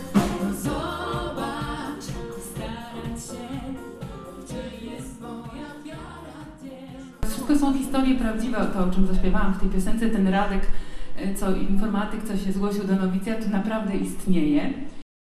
Mogli to odczuć podczas kolejnej edycji Qulturalnego Bierzmowania, która odbyła się w legionowskim ratuszu.
Dla młodych zagrał zespół „DlaTego”, mający w swoim repertuarze muzykę chrześcijańską